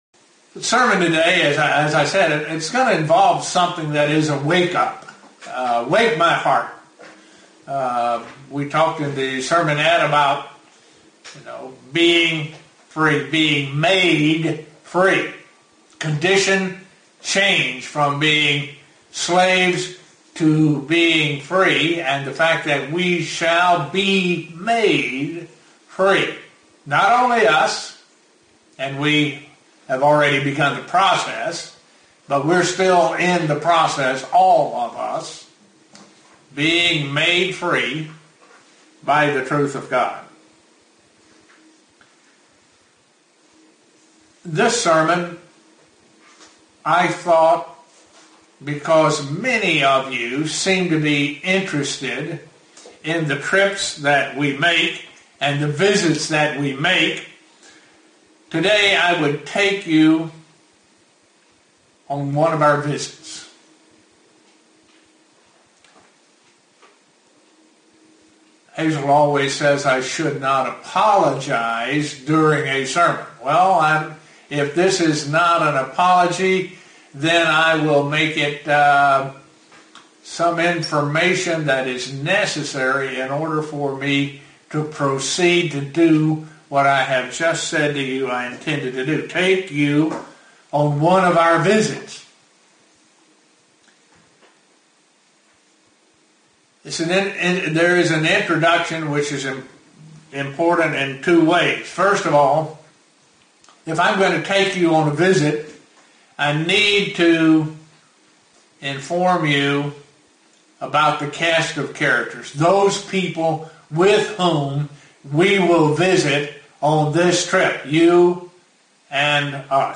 An answer to the question: Are all Christians going to be in the first resurrection? (Broadcast from Estonia)
(Broadcast from Estonia) UCG Sermon Studying the bible?